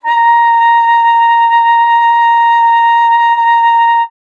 42e-sax12-a#5.wav